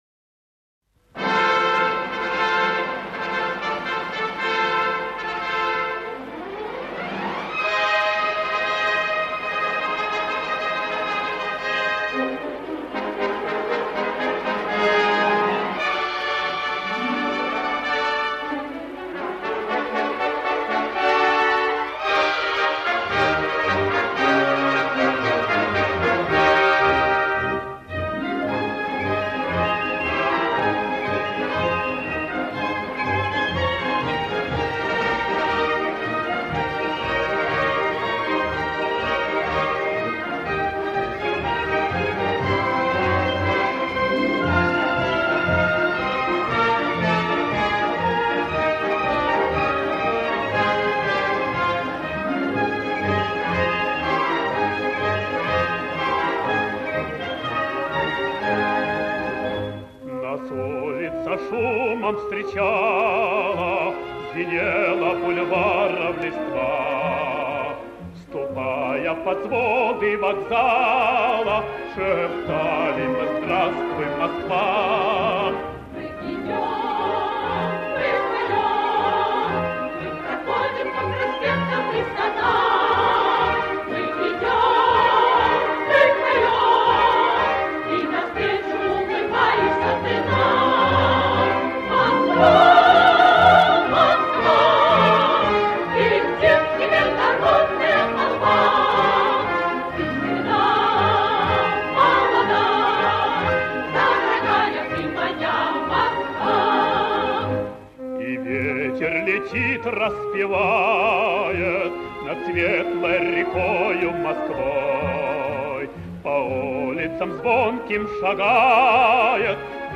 Запись с пластинки лучшего качества
хор и оркестр.